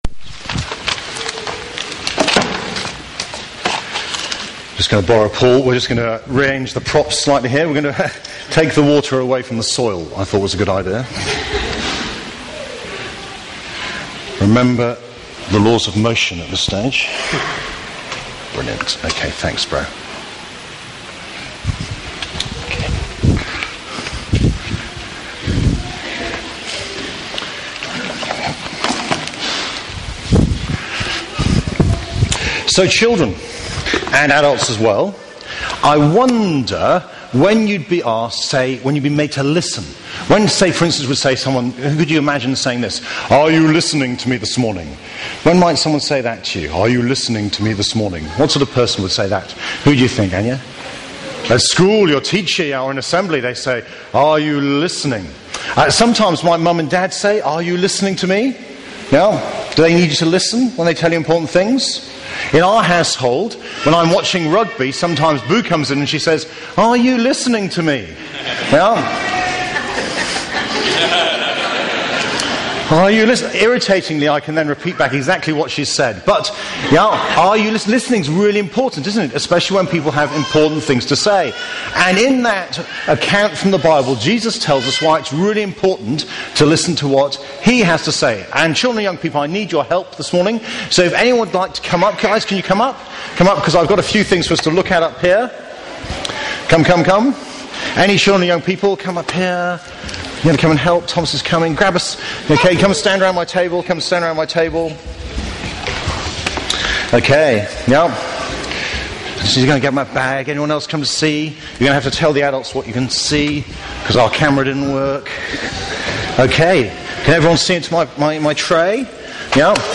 Sermons Archive - Page 105 of 188 - All Saints Preston